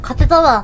Worms speechbanks
Flawless.wav